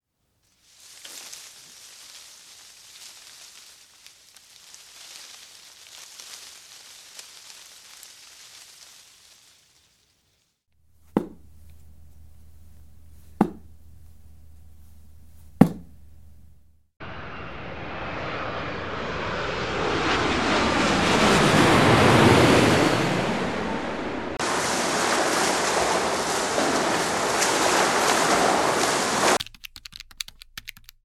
Колонка проигрывала странные звуки.